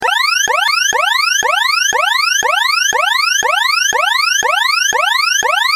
دانلود صدای هشدار 24 از ساعد نیوز با لینک مستقیم و کیفیت بالا
جلوه های صوتی